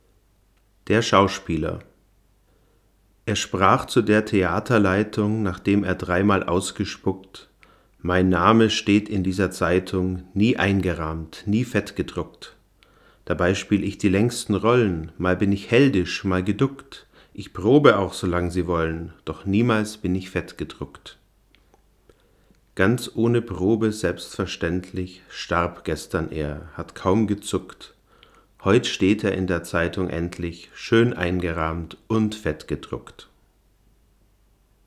Ich habe jetzt noch eine weitere Reihe gemacht, diesmal mit dem Rode NTG-2 aus ebenfalls ca. 60 cm Abstand.
Mikrofon am PreAmp M-Audio DMP2 (dann über Line-Eingang in Tascam DR-40)